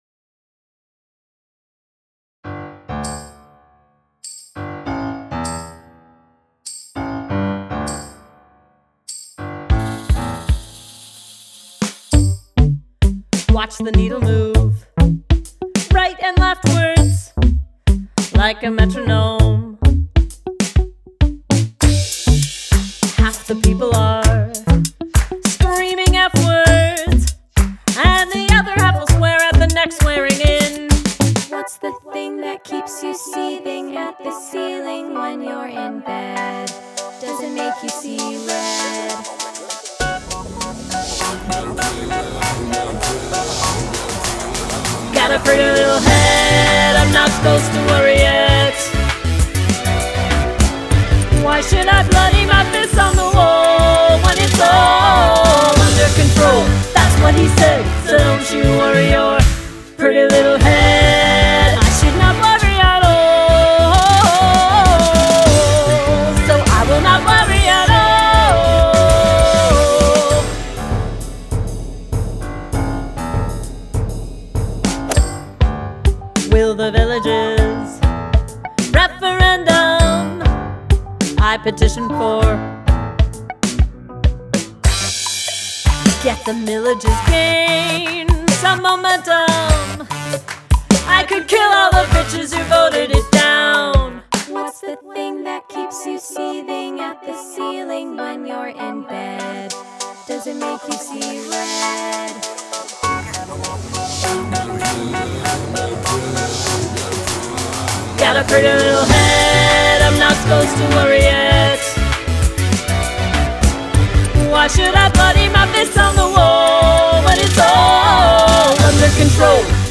lead vocals
additional vocals